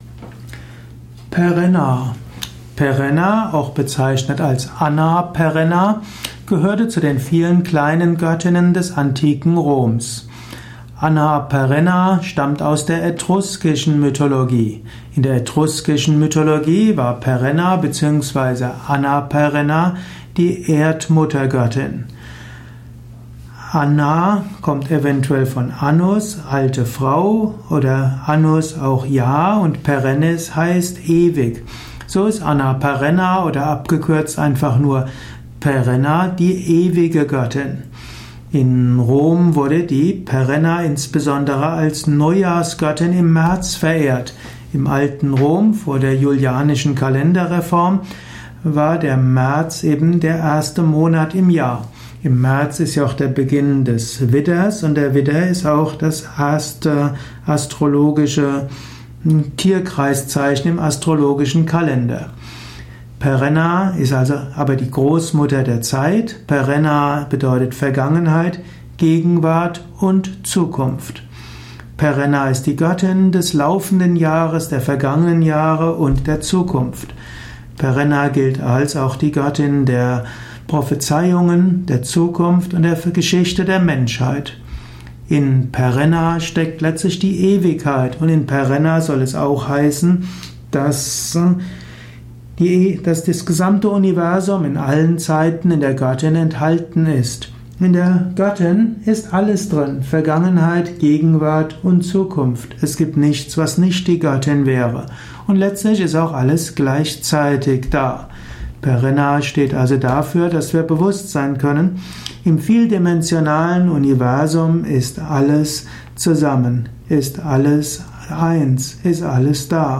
Ein Audio Vortrag über Perenna, einer italischen Göttin. Mit Überlegungen über die Bedeutung von Perenna in der italischen Mythologie, im italischen Pantheon.
Dies ist die Tonspur eines Videos, zu finden im Yoga Wiki.